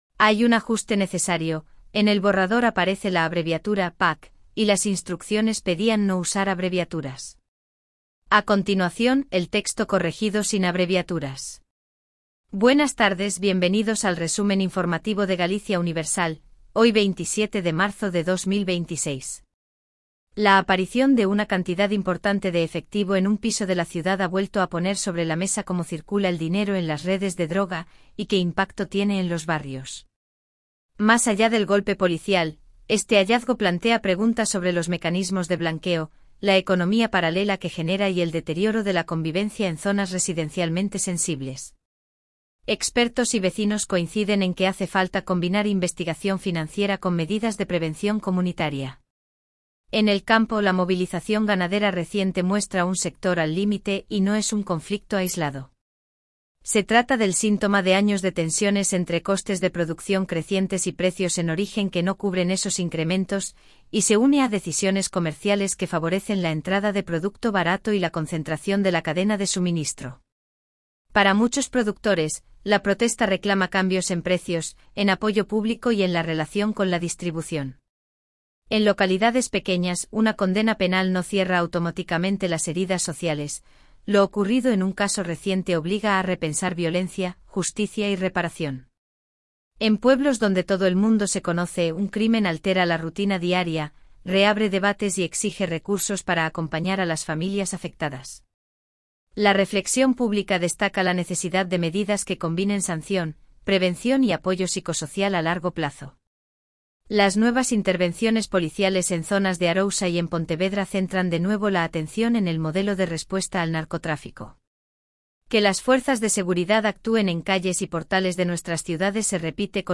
🎙 PODCAST DIARIO
Resumo informativo de Galicia Universal